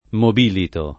mobilito [ mob & lito ]